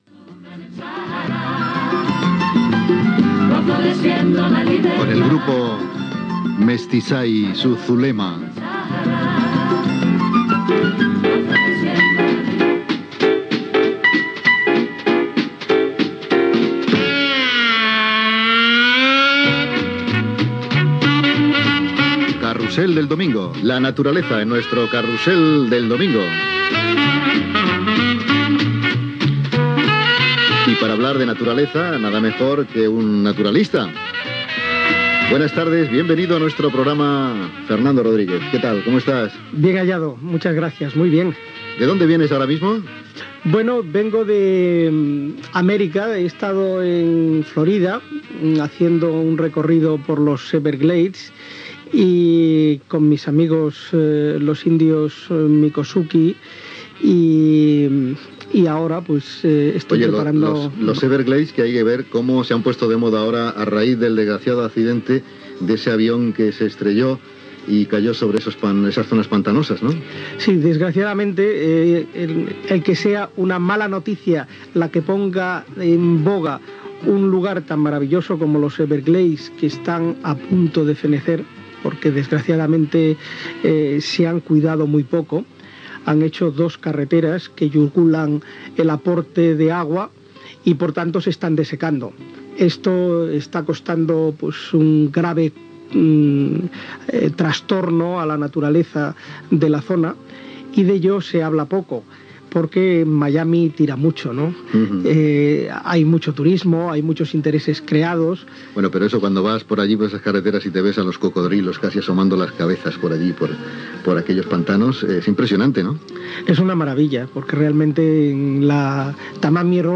Tema musical, identificació del programa i entrevista